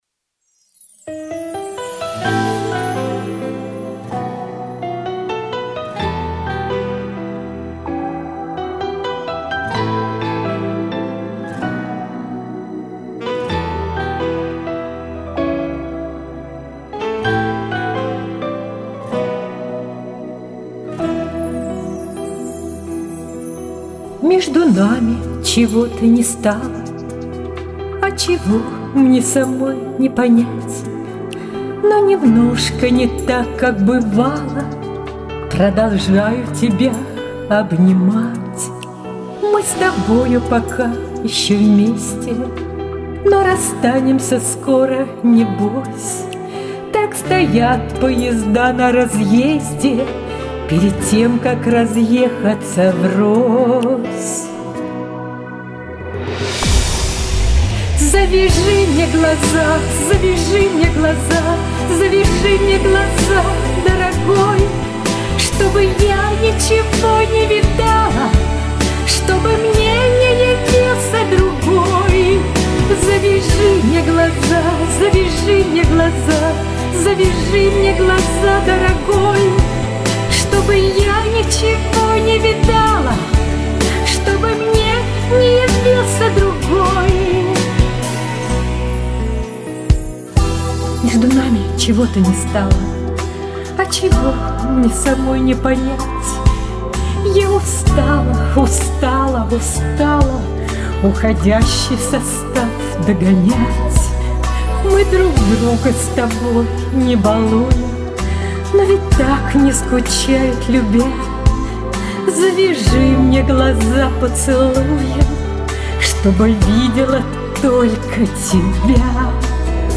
Эмоционально исполнили хорошие песни.